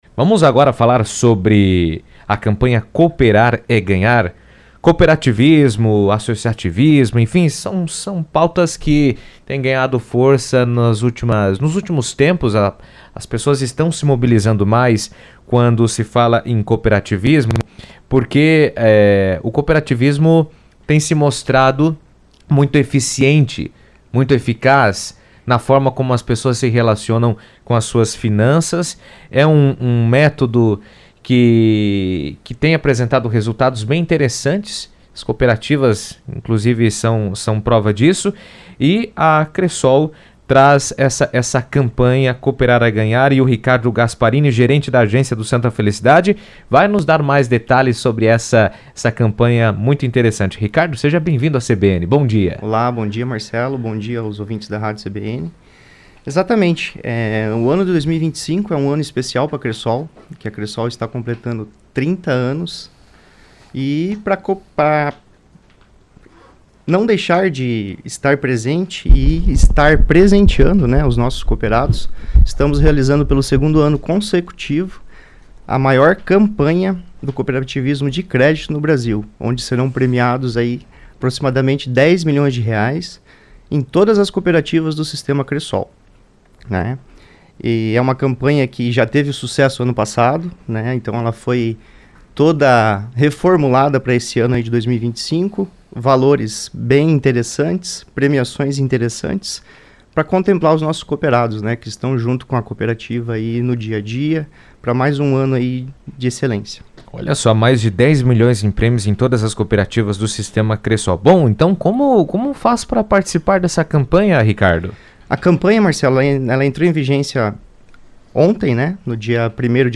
esteve na CBN Cascavel e deu detalhes da campanha.